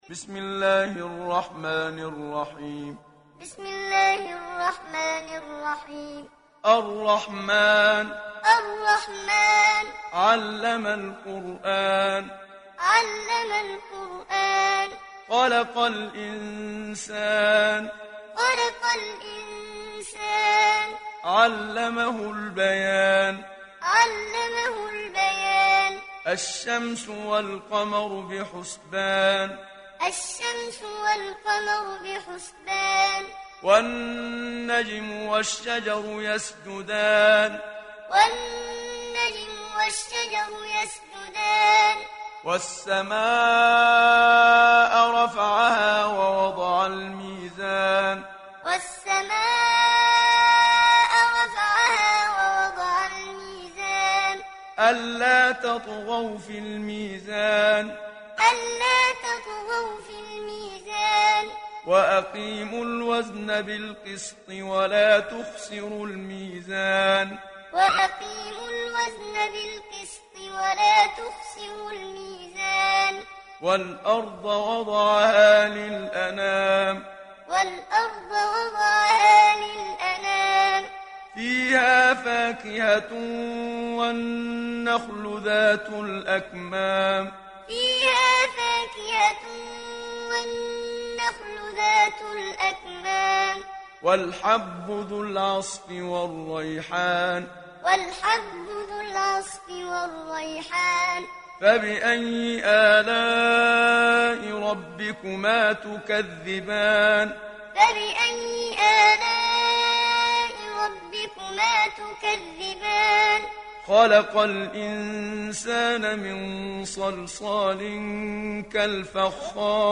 دانلود سوره الرحمن محمد صديق المنشاوي معلم